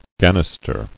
[gan·nis·ter]